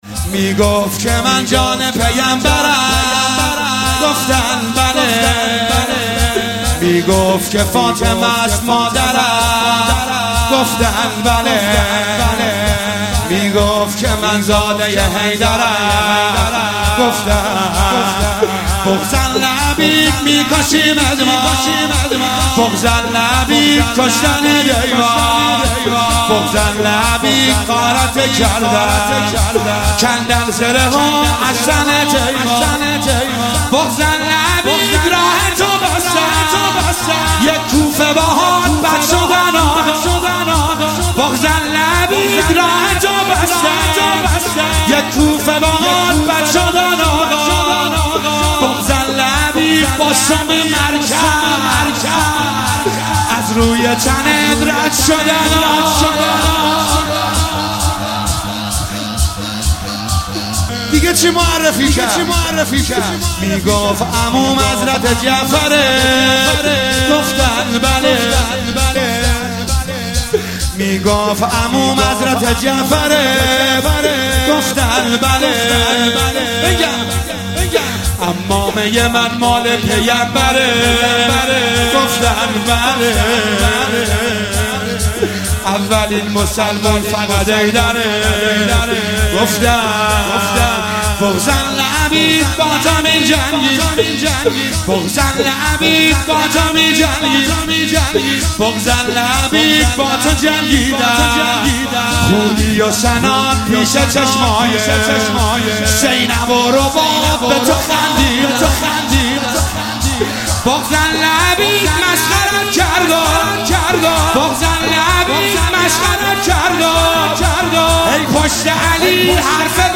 شب هفتم محرم1401